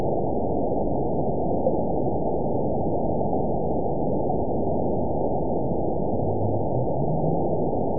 event 920888 date 04/13/24 time 06:36:46 GMT (1 year ago) score 7.74 location TSS-AB02 detected by nrw target species NRW annotations +NRW Spectrogram: Frequency (kHz) vs. Time (s) audio not available .wav